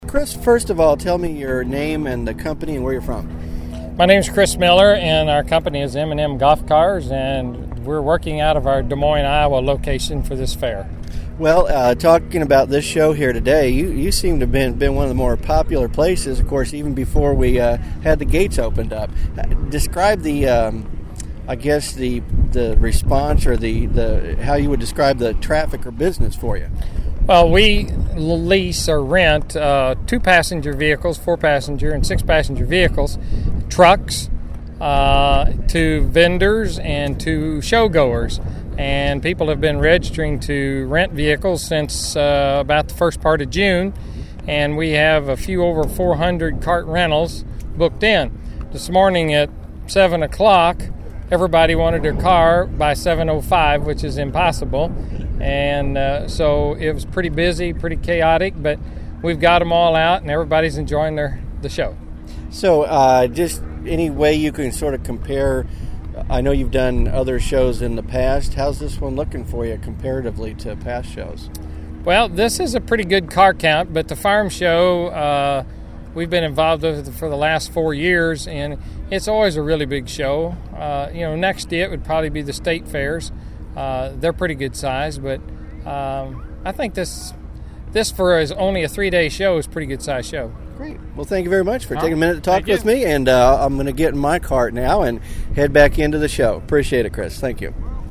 AgWired coverage of the 2008 Farm Progress Show